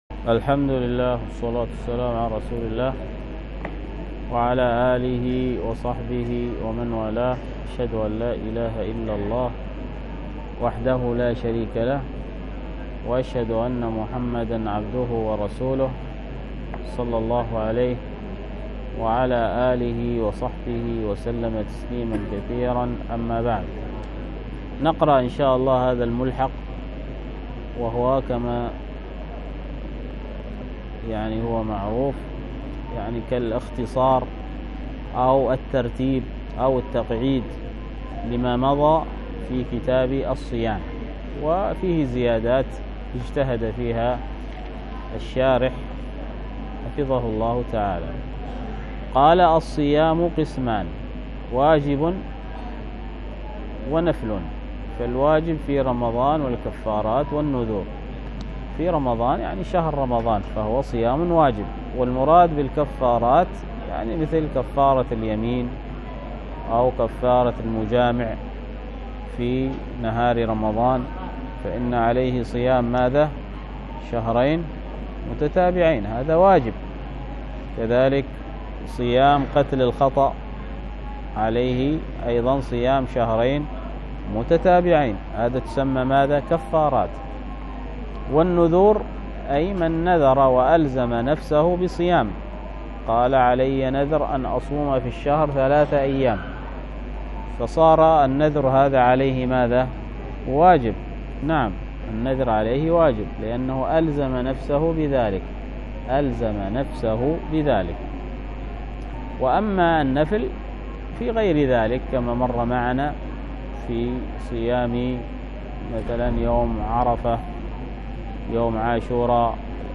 الدرس في الصحيح المسند مما ليس في الصحيحين 131، ألقاها